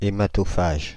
Ääntäminen
Synonyymit sanguinivore Ääntäminen Paris: IPA: [e.ma.tɔ.faʒ] Tuntematon aksentti: IPA: /e.ma.to.faʒ/ Haettu sana löytyi näillä lähdekielillä: ranska Käännös Adjektiivit 1. haematophagous 2. hematophagous Suku: f .